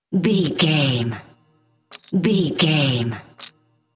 Casino 3 chips table x3
Sound Effects
foley